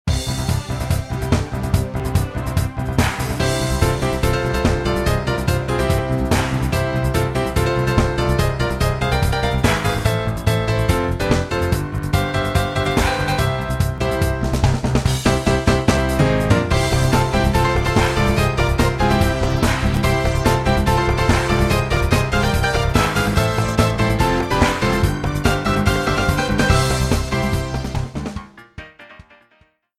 Credits music